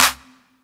Clap (Stay With Me).wav